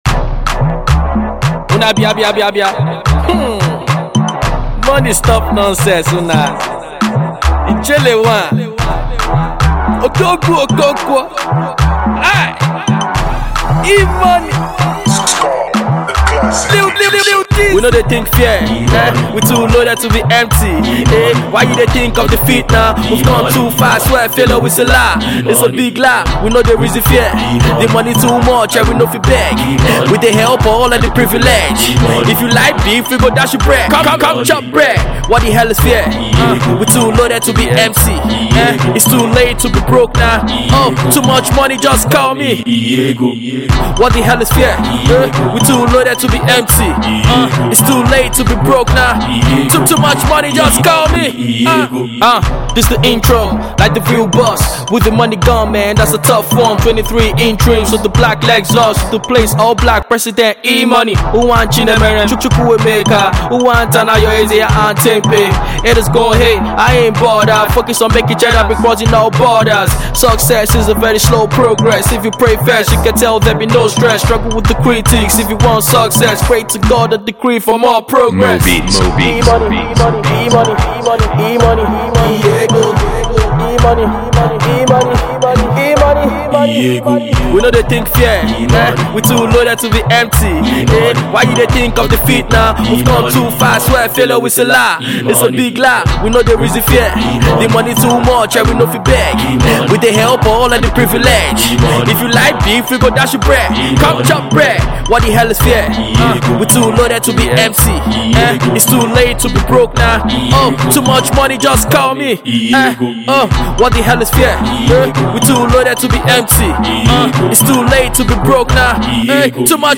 serenading tune
head-bopping tune